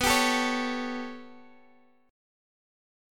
B7sus2#5 chord